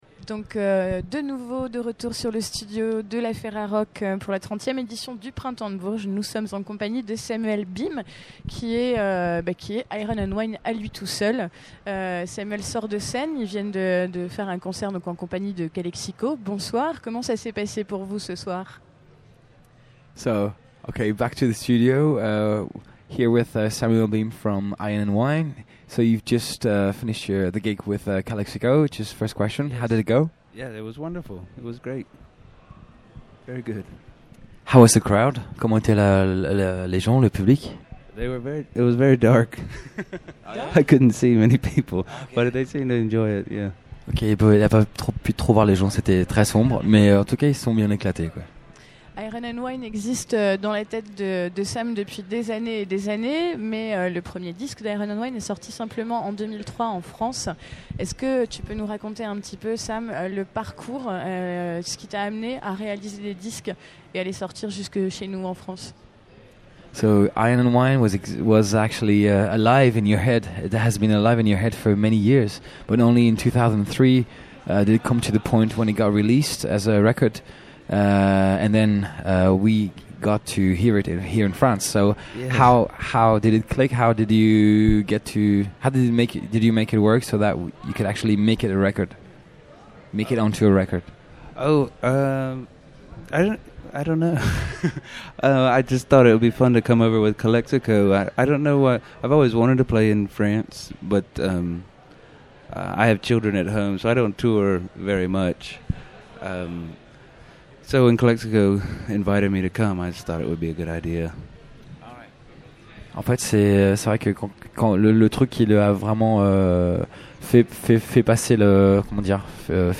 Iron and Wine Festival du Printemps de Bourges 2006 : 40 Interviews à écouter !